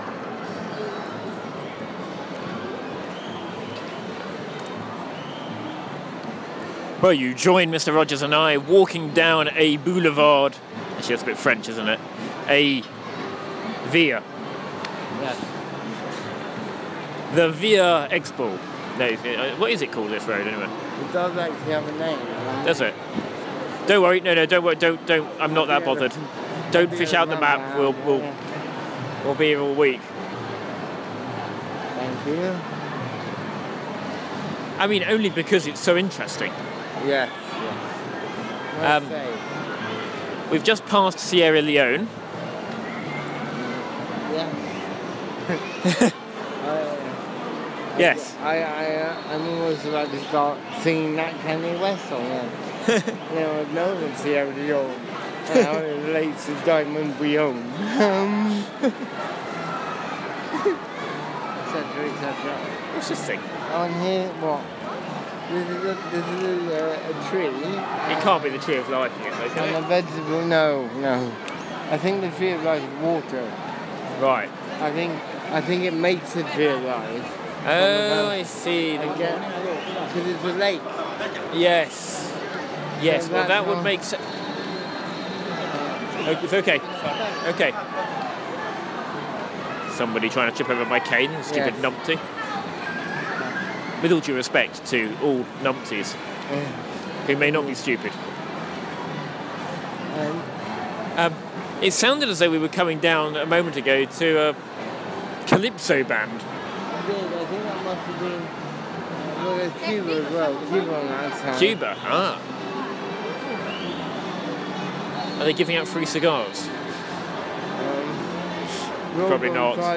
To Sicily: From Sierra Leone - a walk through the Milan Expo 2015 site